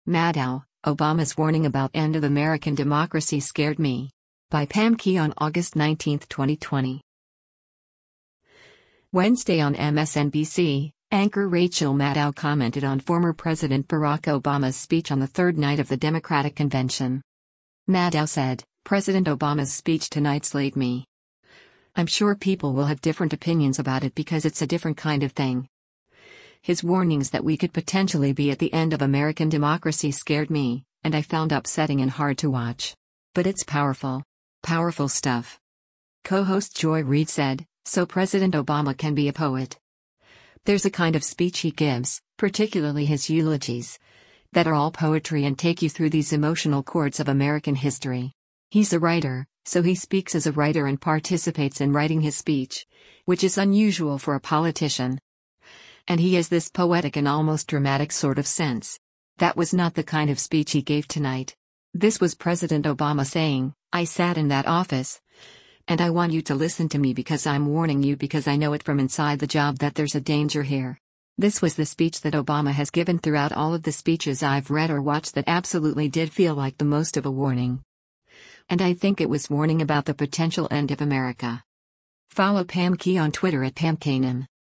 Wednesday on MSNBC, anchor Rachel Maddow commented on former President Barack Obama’s speech on the third night of the Democratic convention.